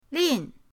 lin4.mp3